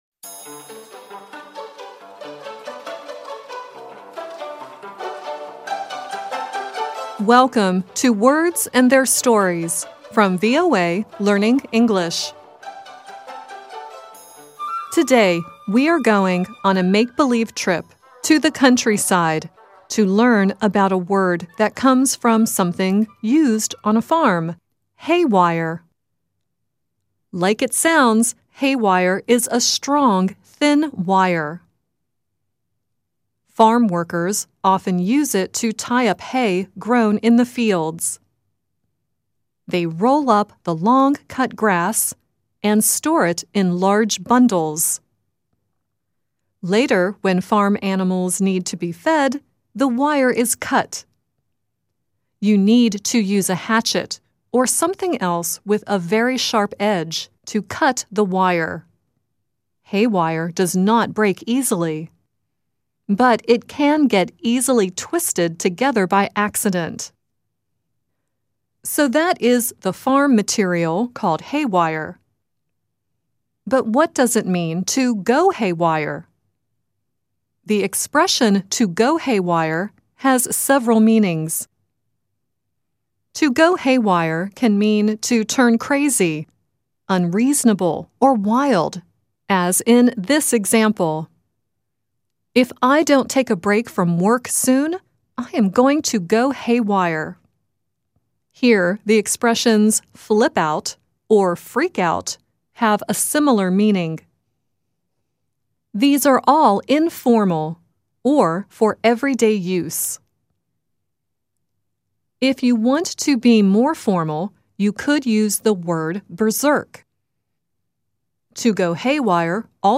The song at the end is “Fools Button” by Jimmy Buffet.